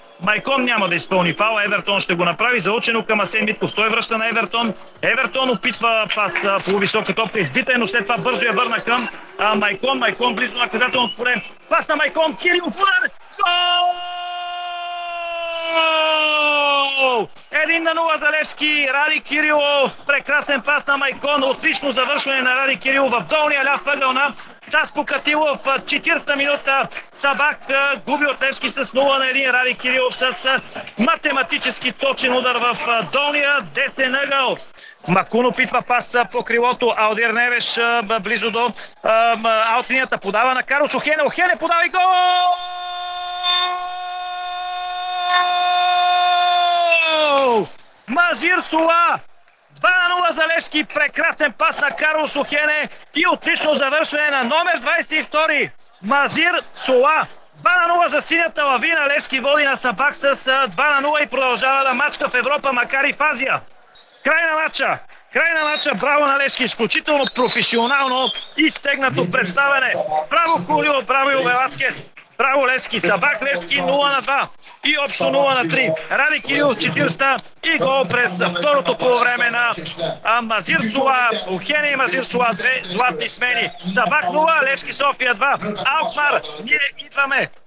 Емоцията в ефира на Дарик радио при головете на Радослав Кирилов и Мазир Сула (АУДИО)